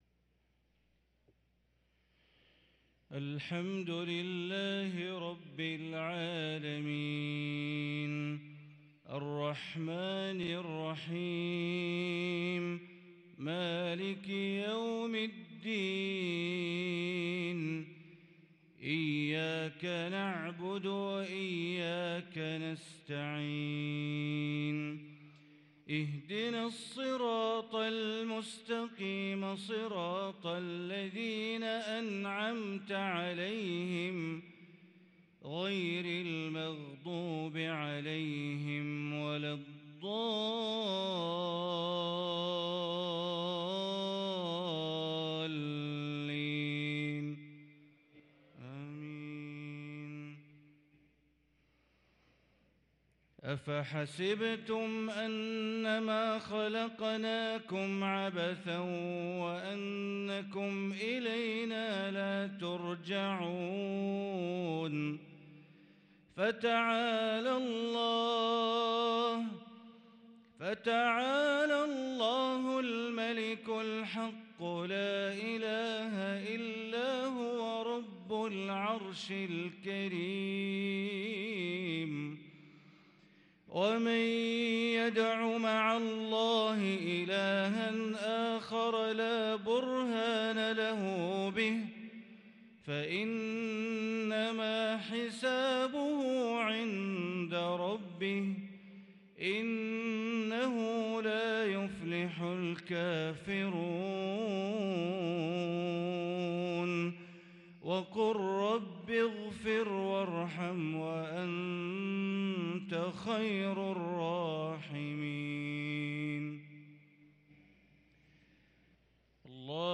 صلاة المغرب للقارئ بندر بليلة 28 جمادي الأول 1444 هـ